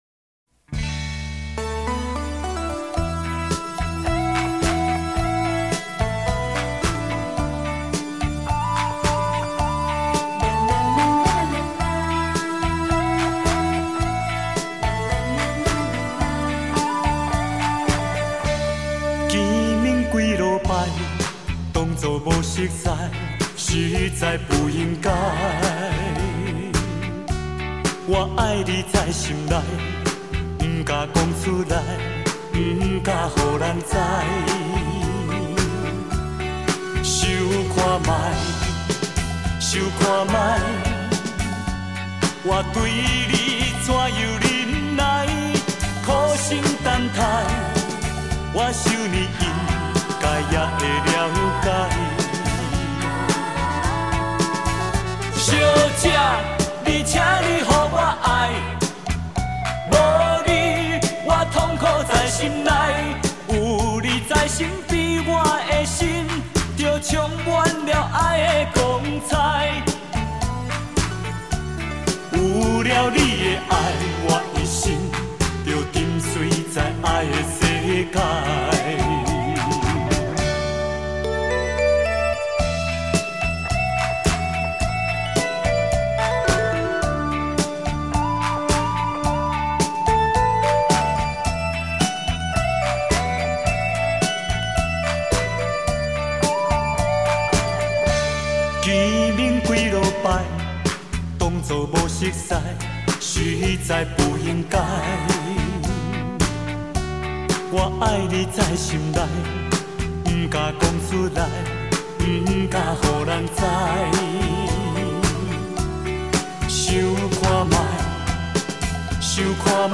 狂热的歌声